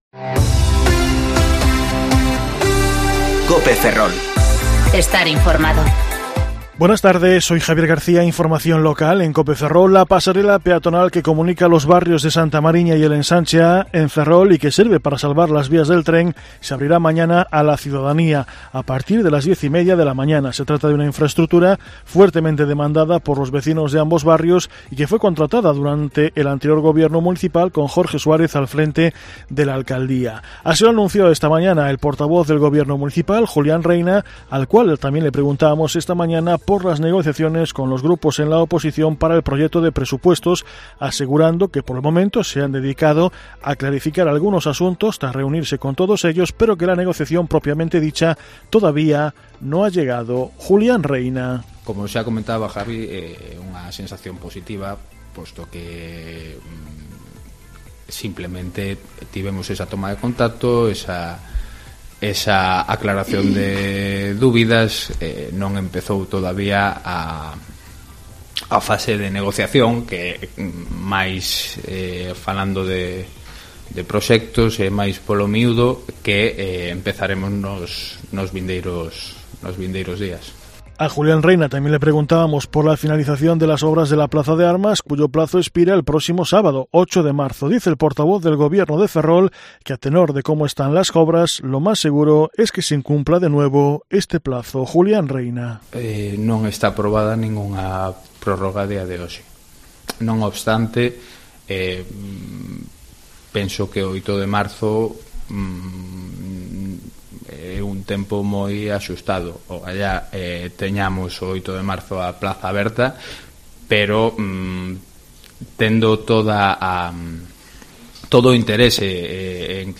Informativo Mediodía COPE Ferrol - 2/3/2020 (De 14,20 a 14,30 horas)